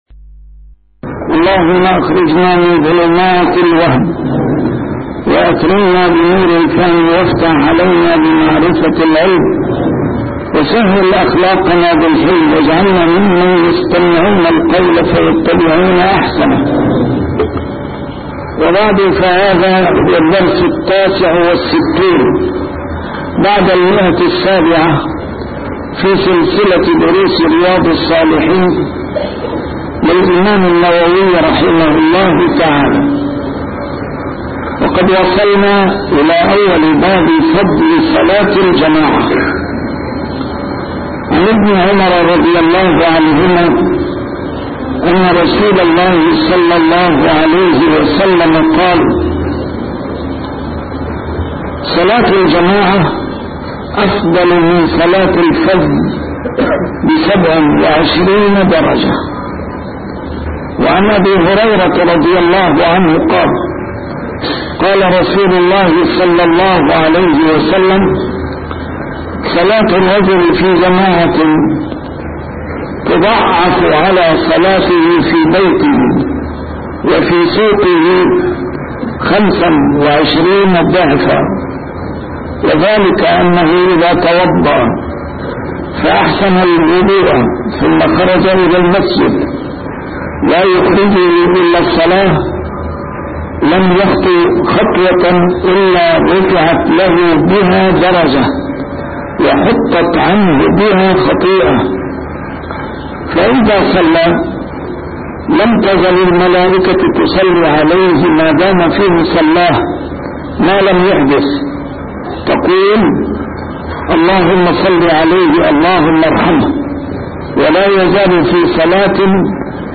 A MARTYR SCHOLAR: IMAM MUHAMMAD SAEED RAMADAN AL-BOUTI - الدروس العلمية - شرح كتاب رياض الصالحين - 769- شرح رياض الصالحين: فضل صلاة الجماعة